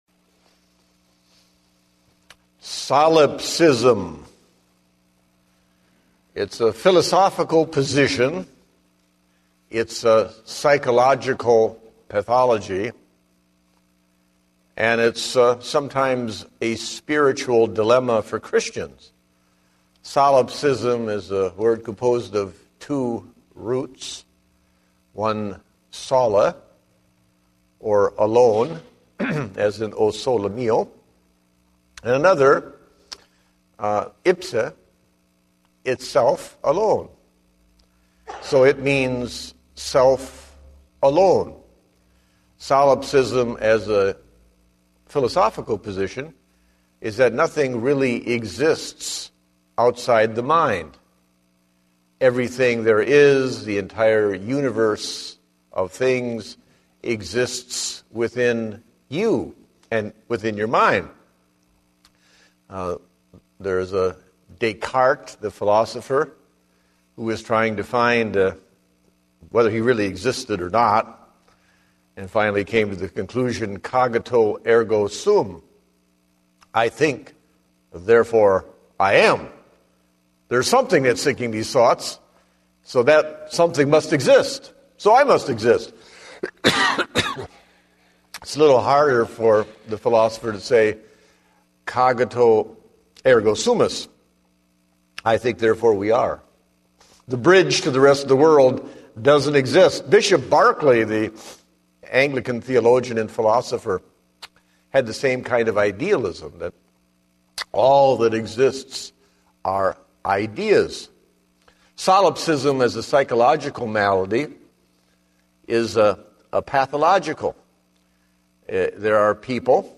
Date: November 28, 2010 (Evening Service)